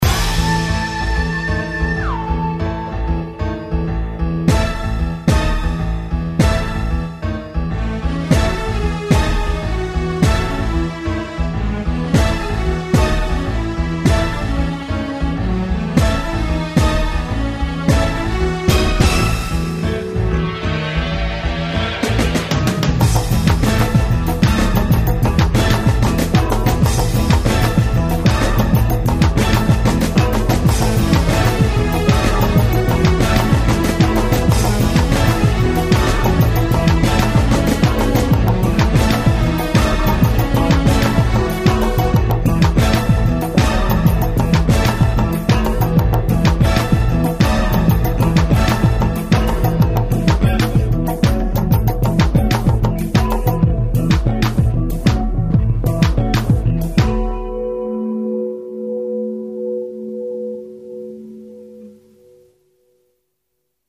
oompah and belly dance